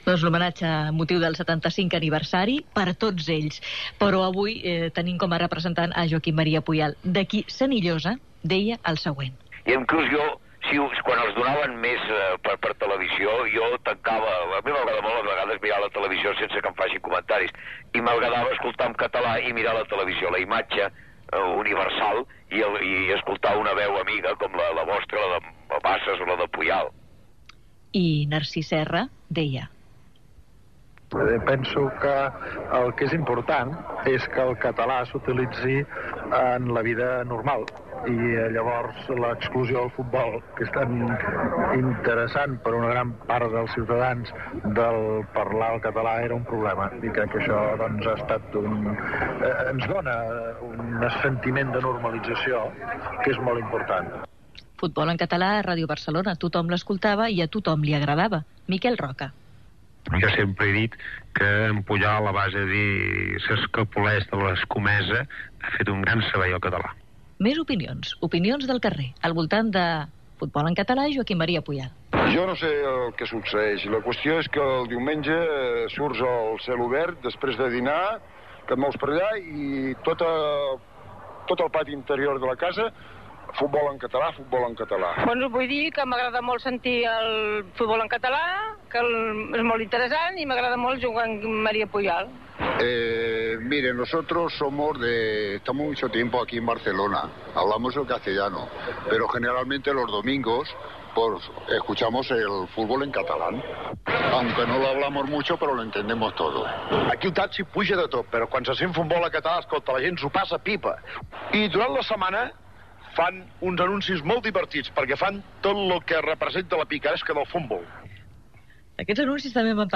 Entrevista a Joaquim Maria Puyal, sobre "Futbol en català" a Ràdio Barcelona.
Opinions dels oients. Puyal explica algunes entrevistes que havia fet a l'emissora.